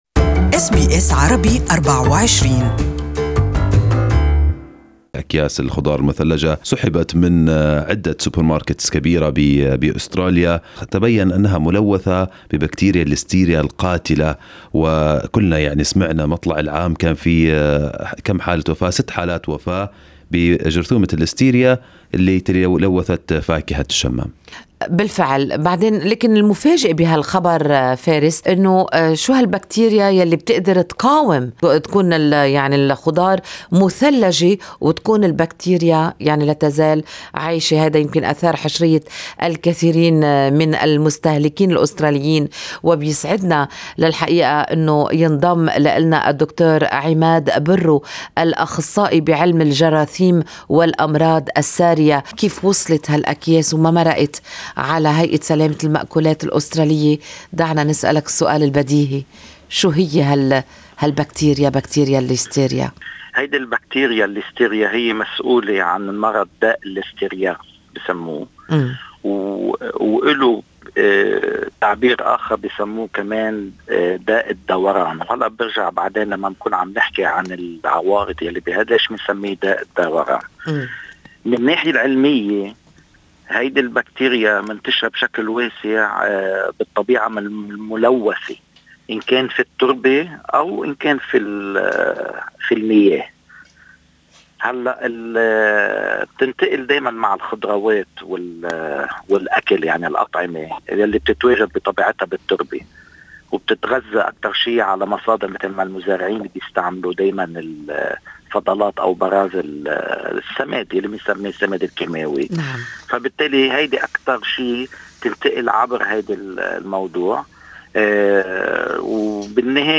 Good Morning Australia interviewed Microbiologist